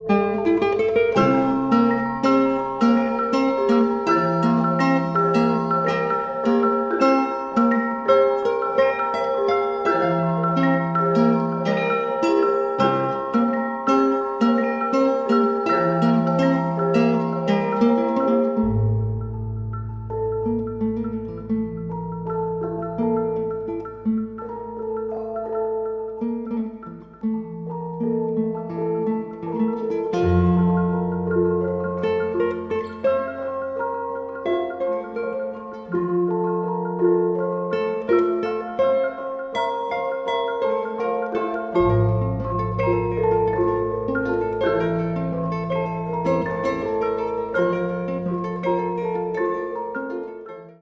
just intonation guitar